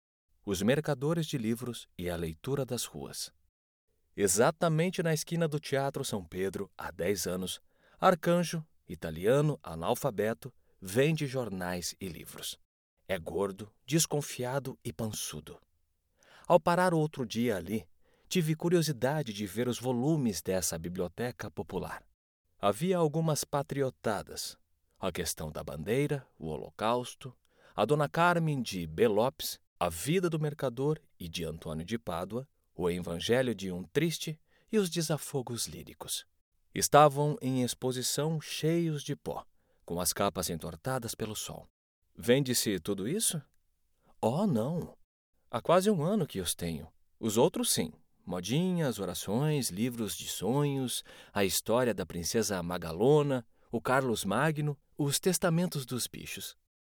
Com uma gama de graves e contralto e configuração de estúdio profissional, ele oferece serviços de locução amigáveis e especializados para marcas que buscam clareza e autenticidade.
Narração
Focusrite Scarlet Solo + microfone Akg c3000
GravesContralto
DinâmicoNeutroAmigáveisConversacionalCorporativoVersátil